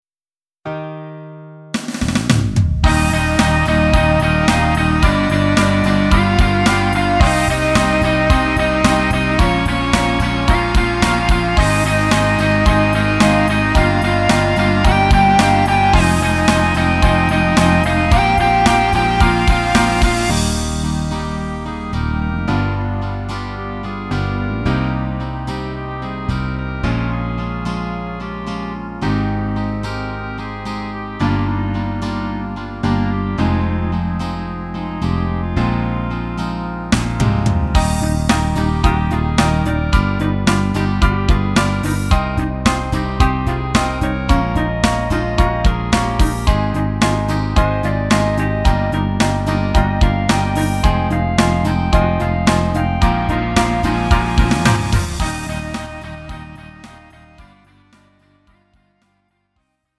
음정 원키 3:57
장르 가요 구분 Lite MR